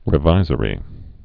(rĭ-vīzə-rē)